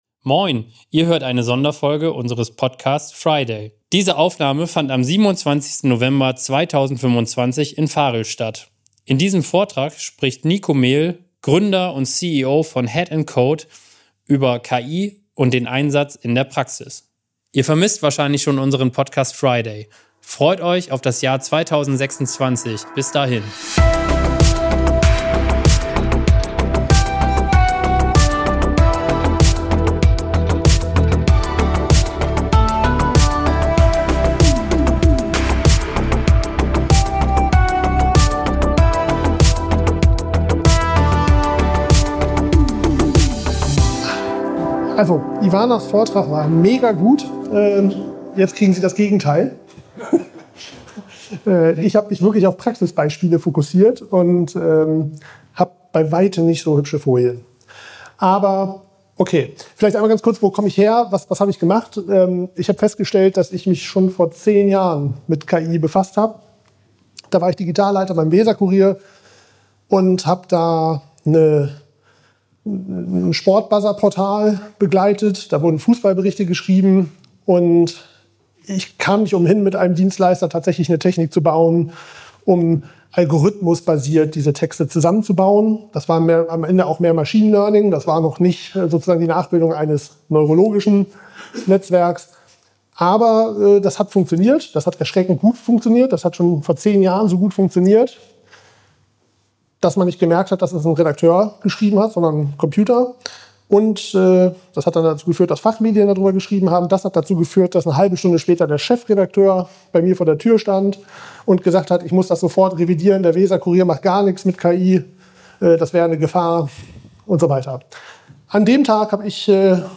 In seiner Keynote zeigt er, wie KI-Vertriebsberater im Shop und autonome KI-Agenten in Prozessen schon heute messbar wirken – mit klaren Architekturen, KPIs und Learnings aus dem Live-Betrieb.